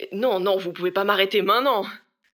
VO_ALL_Interjection_09.ogg